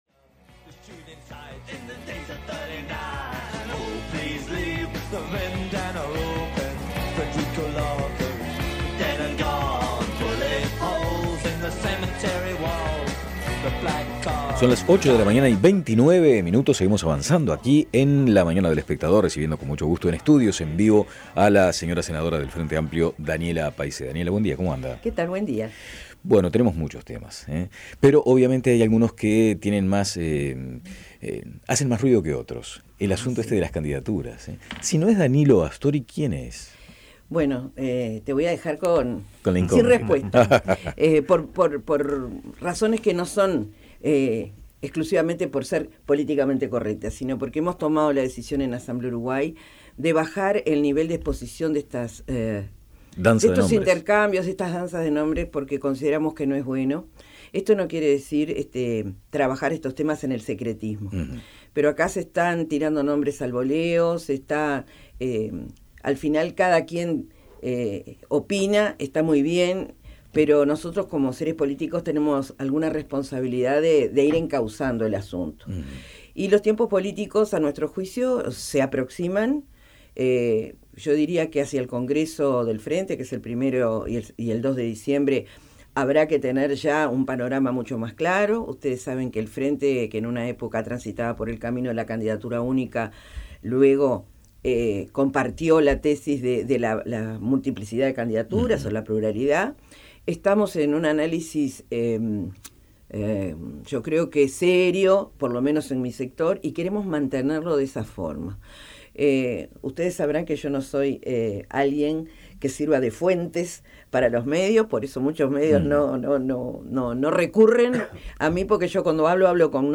Descargar Audio no soportado Escuche la entrevista completa: Descargar Audio no soportado